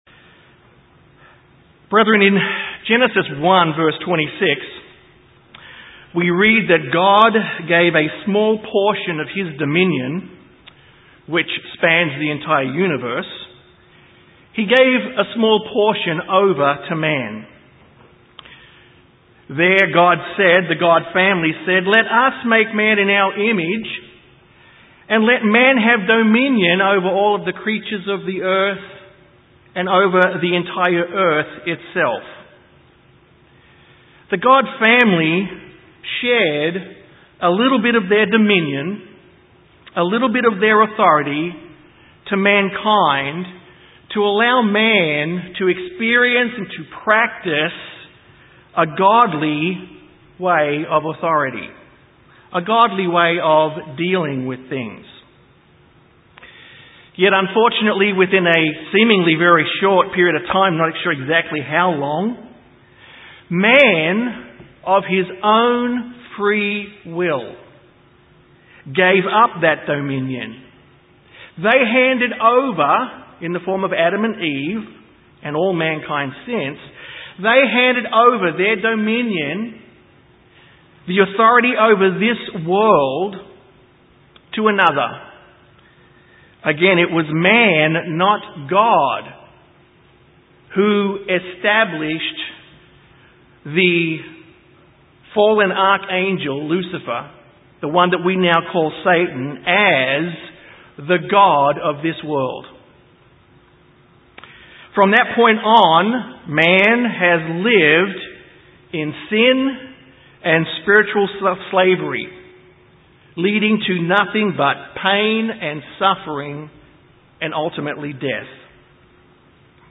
In this sermon we find out how mankind is rescued from this world of darkness into the light of Jesus Christ and God the Father.